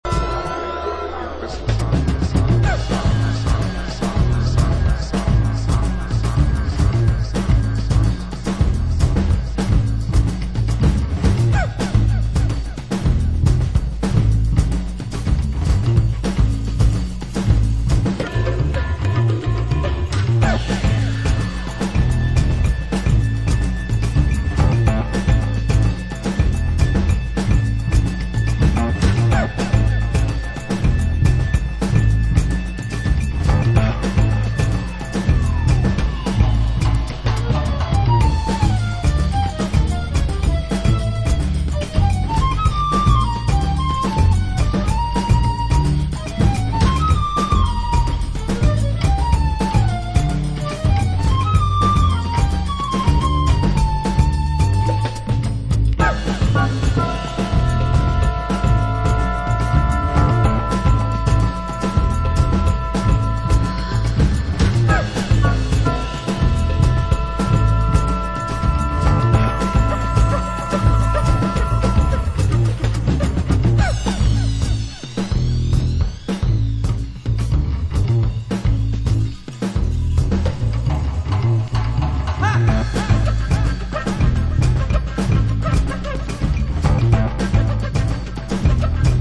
House Soul